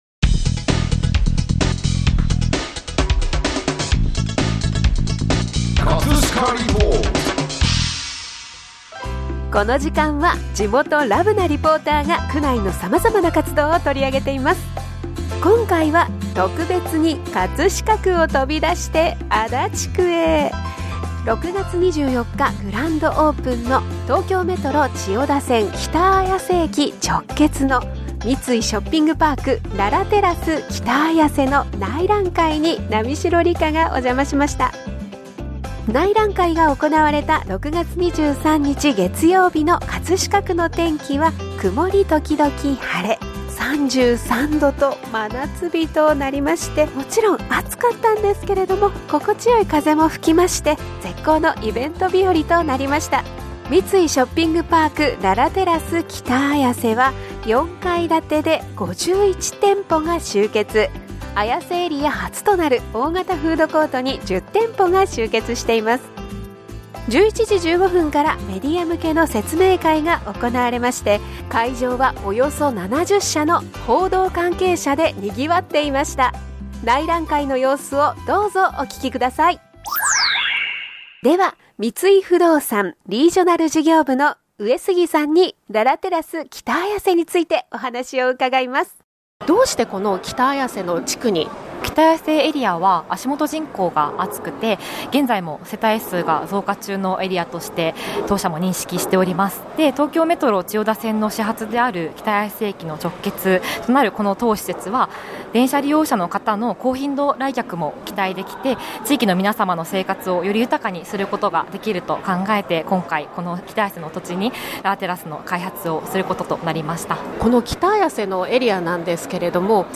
午前11時15分からプレス説明会が行われ、会場はおよそ70の報道関係者でにぎわっていました。 ＜2階フロア＞ ＜2階フロア 札幌味噌Fuji屋> ＜2階フロア 横浜中華街 台湾美食店 886食堂＞ ＜4階フロア 子育てサロン北綾瀬＞ ＜4階フロア ゴルフアルファららテラス北綾瀬＞ ＜4階フロア＞ 内覧会の様子をどうぞお聴きください。